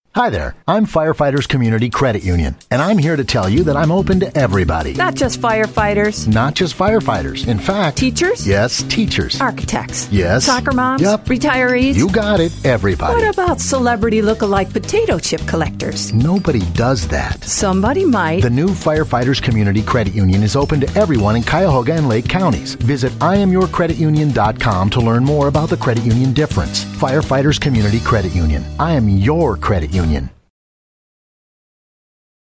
We get a great collection of creative commercials on tracks 4 through 11.